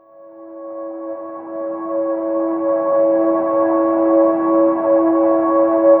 04PAD 01  -R.wav